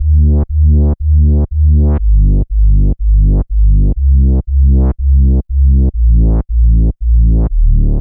Session 14 - Bass.wav